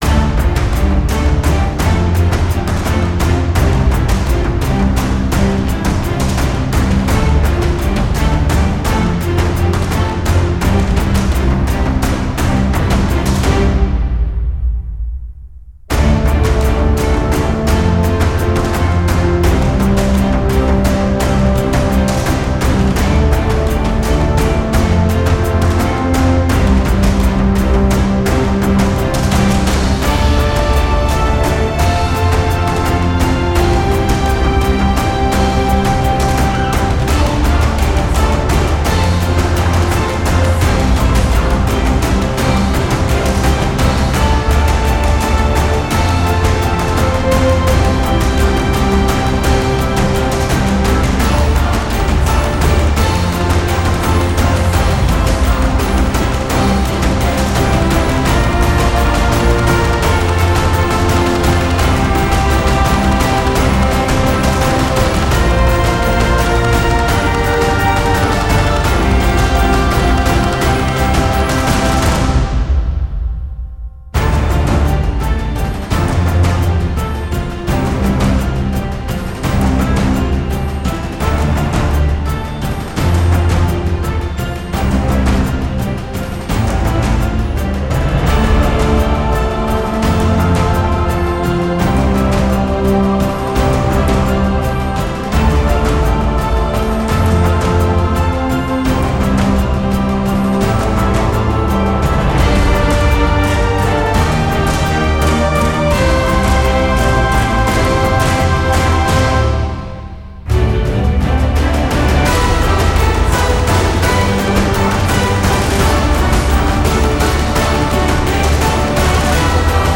I think you nailed it, very Cinematic!